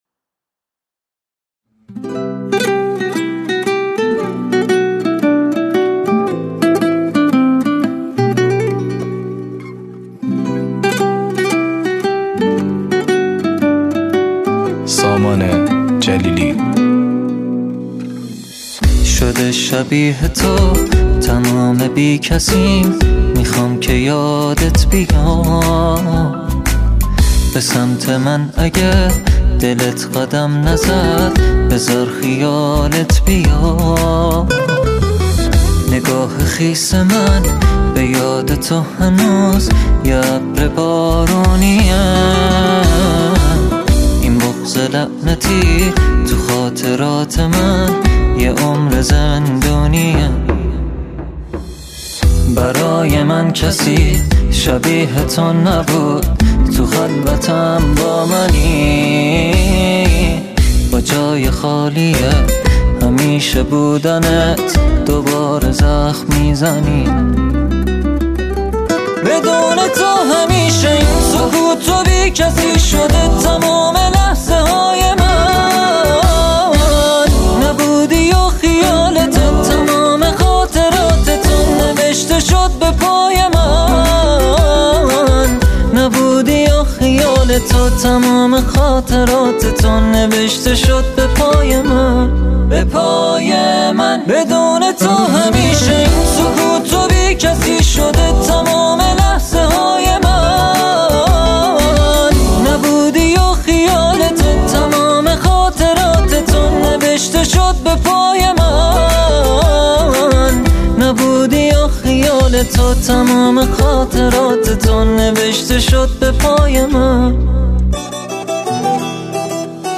بزوکی
گیتار